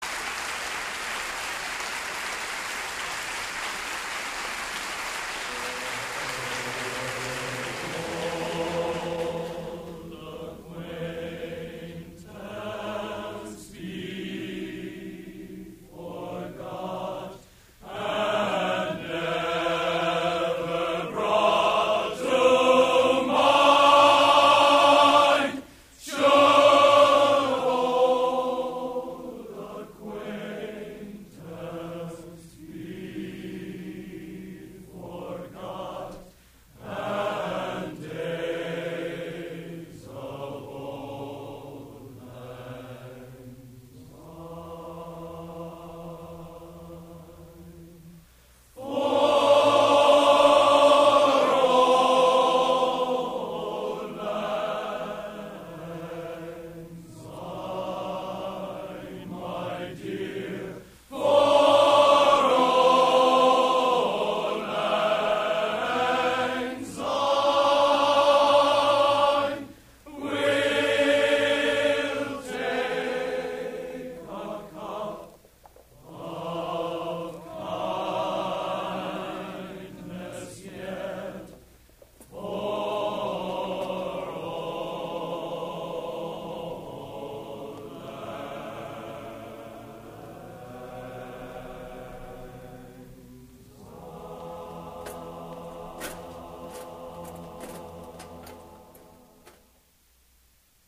Location: West Lafayette, Indiana
Genre: Traditional | Type: End of Season